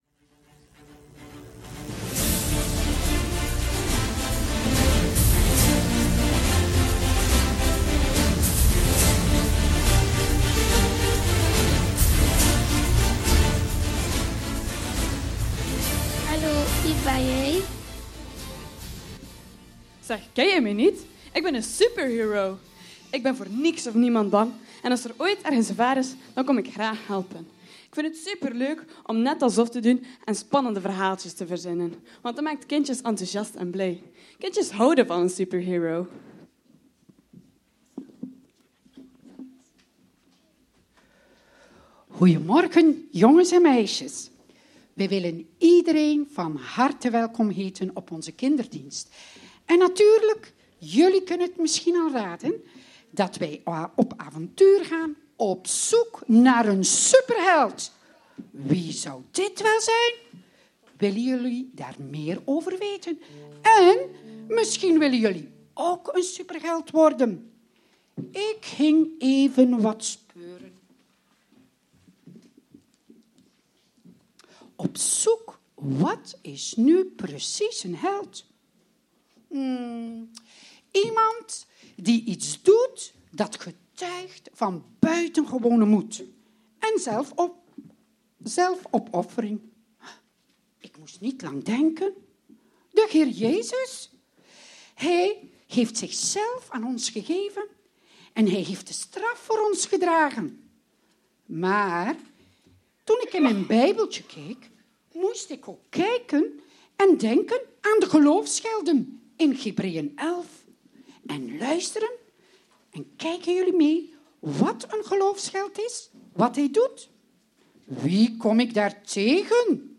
Passage: Sefanja 3 : 17 Dienstsoort: Kinderdienst De HEERE